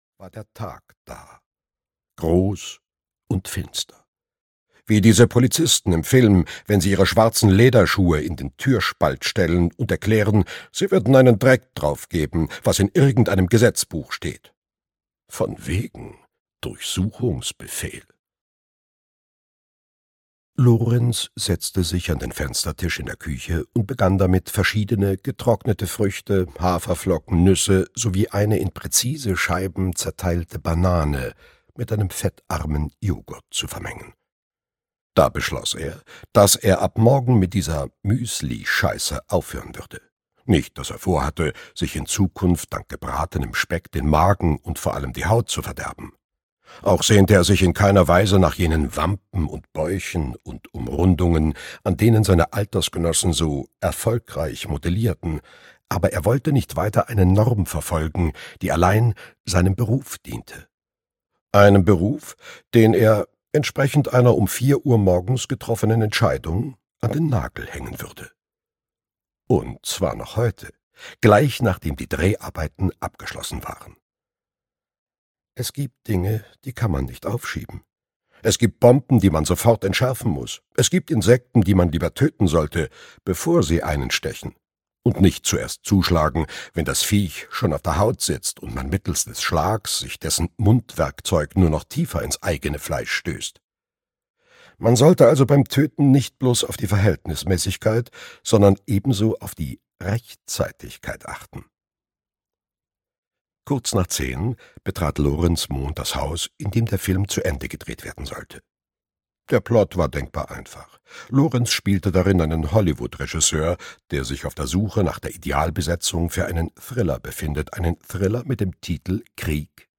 Gewitter über Pluto - Heinrich Steinfest - Hörbuch